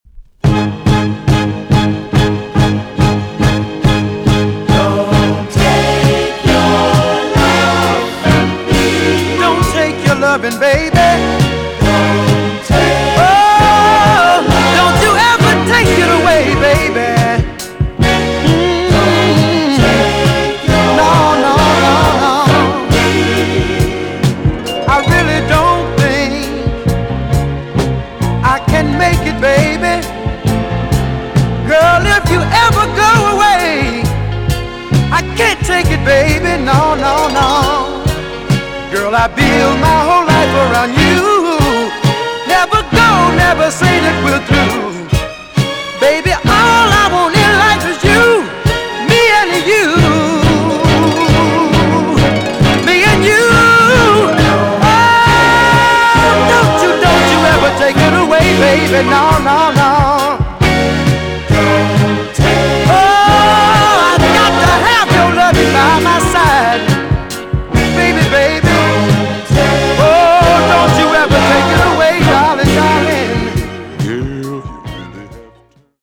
TOP >JAMAICAN SOUL & etc
EX 音はキレイです。